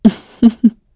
Isolet-Vox_Happy5_kr.wav